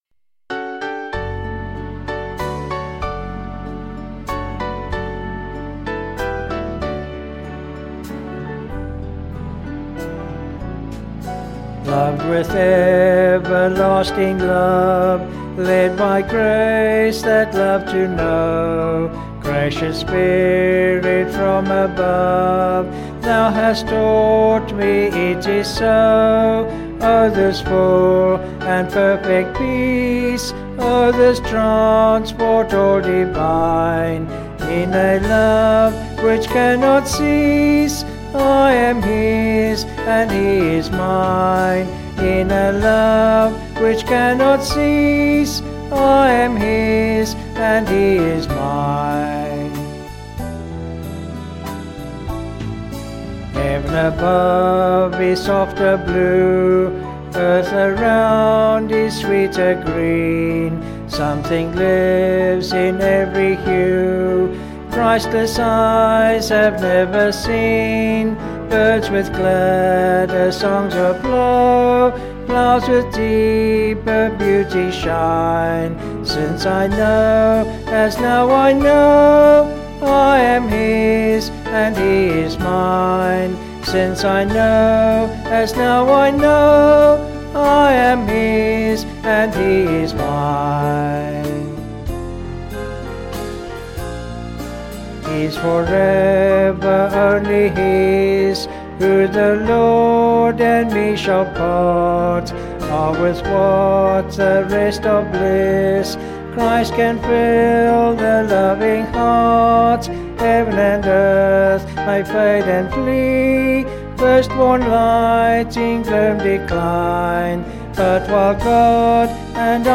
Vocals and Band   264.1kb Sung Lyrics